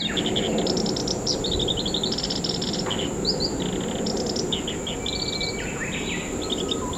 今朝の東観察舎ではコヨシキリのさえずりが聞けました。
はじめは遠くからでしたが段々と近づいてきて最後は観察舎すぐ前の茂みにまで来ました。
茂みの中でさえずるばかり。
本日録音の声はココ（約7秒間）